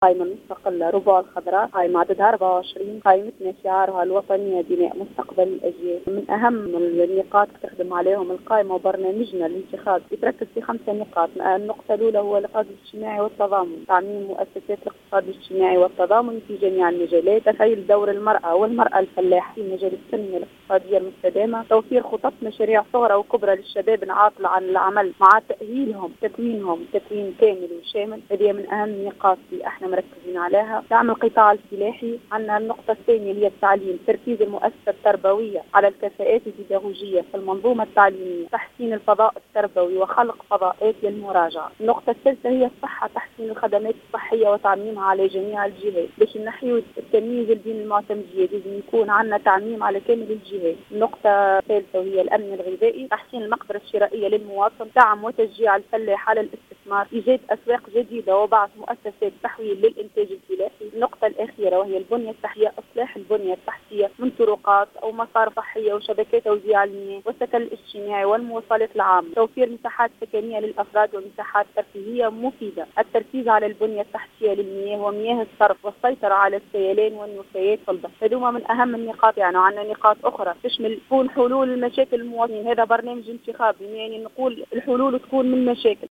في تصريح للجوْهرة أف أم